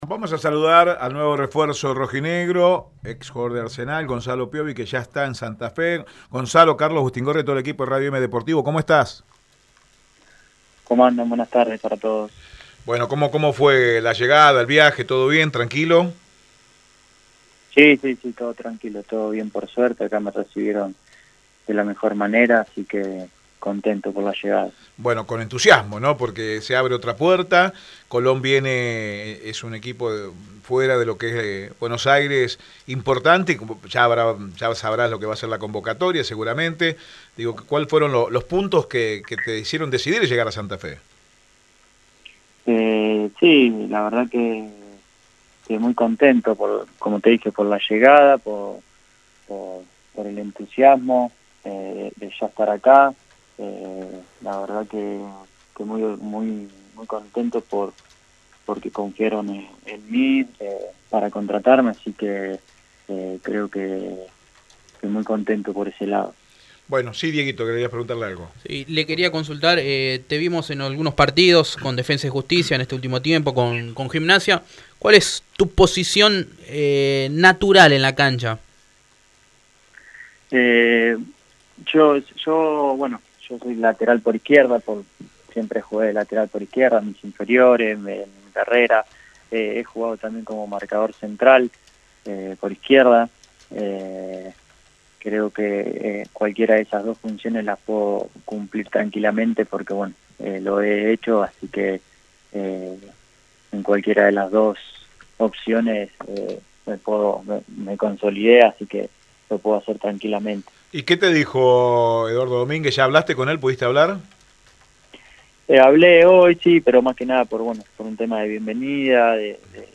habló en exclusiva por Radio Eme Deportivo comentando sus sensaciones de llegar a la institución donde quedará ligado hasta Diciembre de 2021.